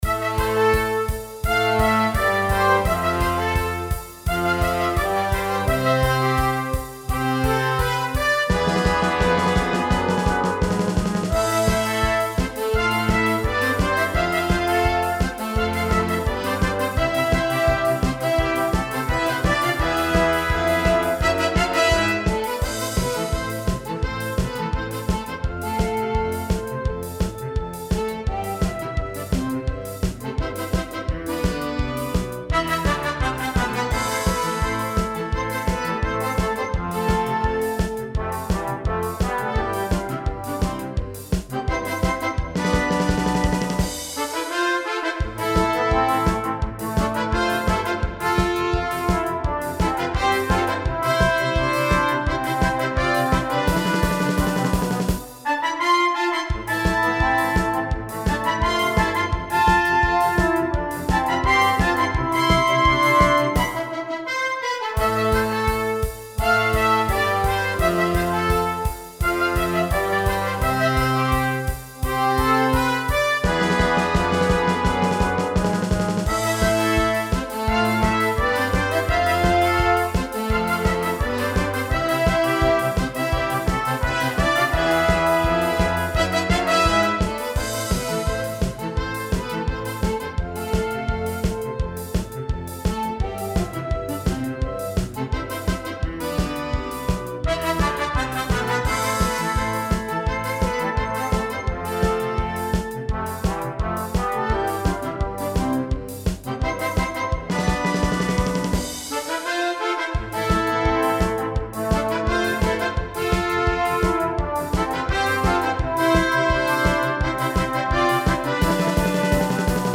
Type de formation : Fanfare / Harmonie / Banda
Pré-écoute non téléchargeable · qualité réduite